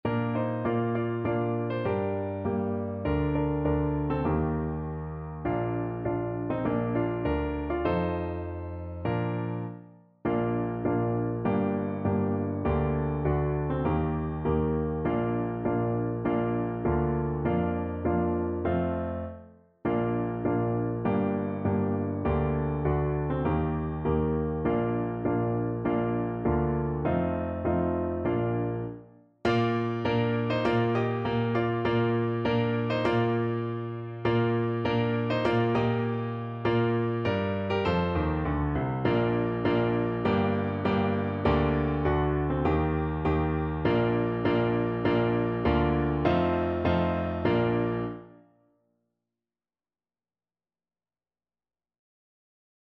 4/4 (View more 4/4 Music)
Moderato
Traditional (View more Traditional Clarinet Music)